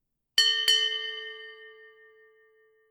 Ship Bell Two Chimes
bell ding dong fire-bell naval ships-bell sound effect free sound royalty free Nature